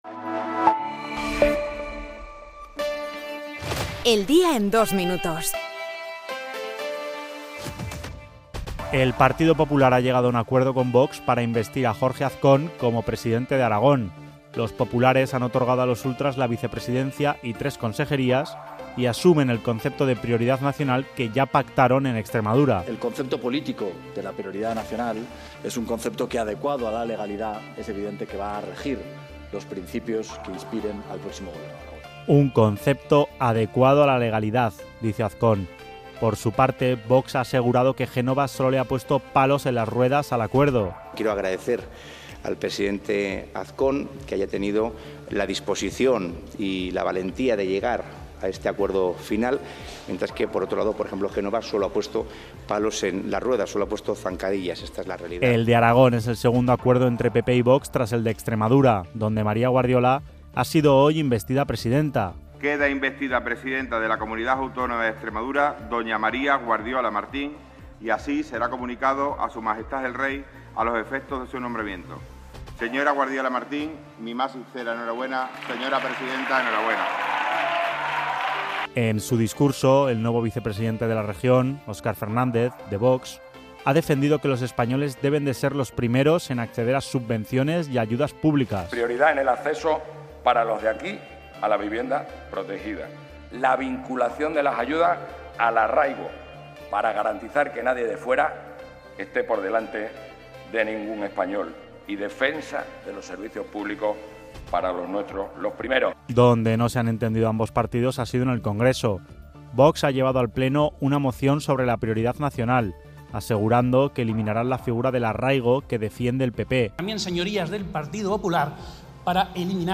Las noticias de la SER
El resumen de las noticias de hoy